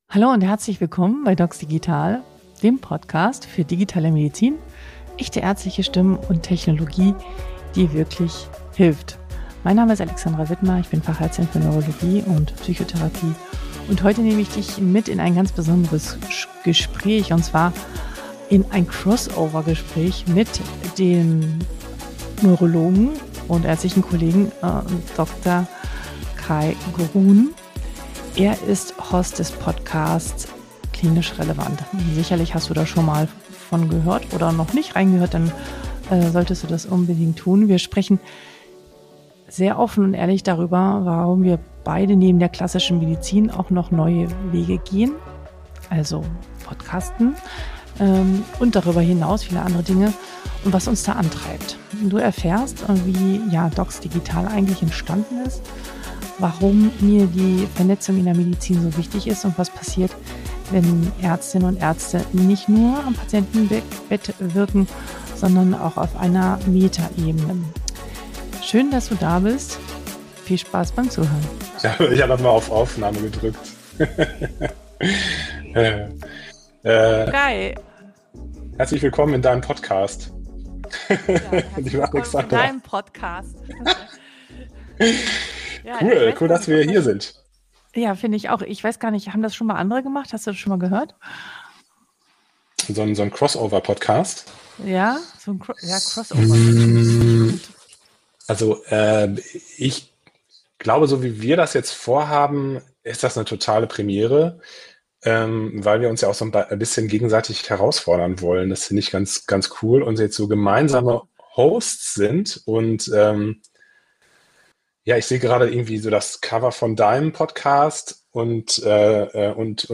Ein Gespräch zwischen zwei Neurolog:innen, das nicht fachlich, aber umso menschlicher ist.